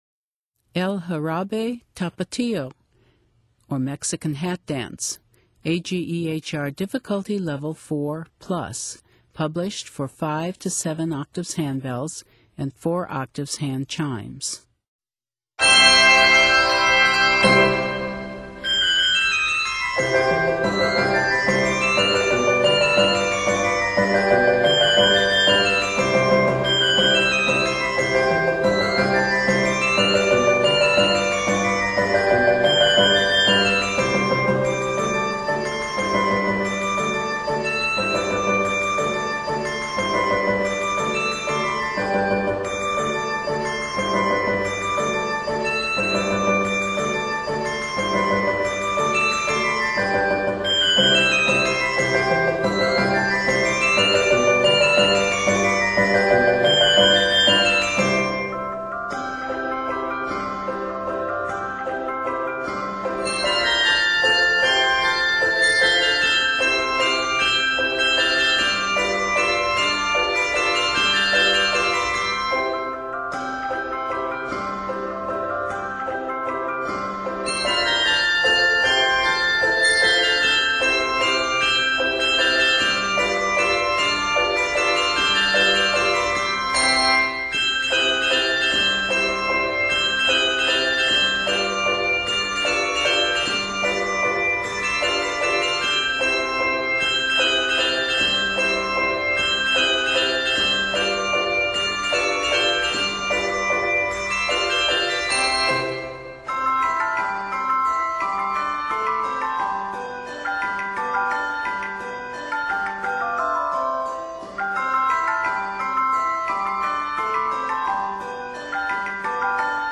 Traditional Mexican Folk Song Arranger
Octaves: 5-7